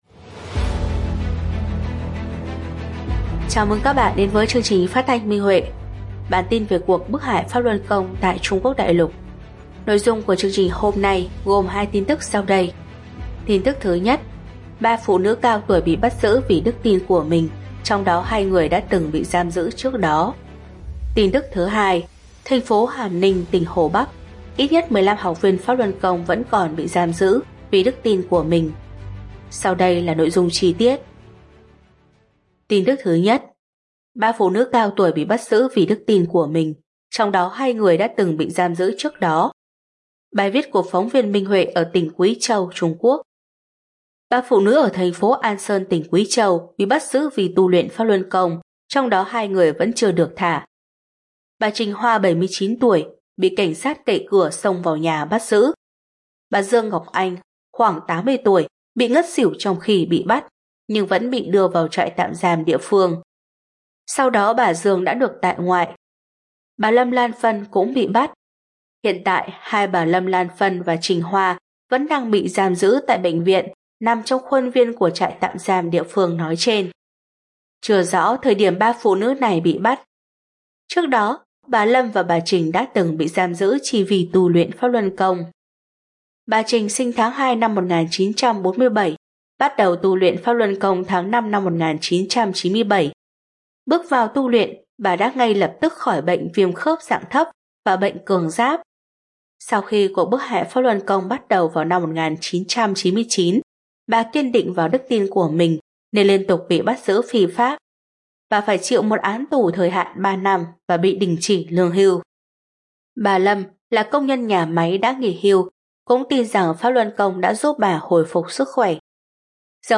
Chương trình phát thanh số 276: Tin tức Pháp Luân Đại Pháp tại Đại Lục – Ngày 15/1/2026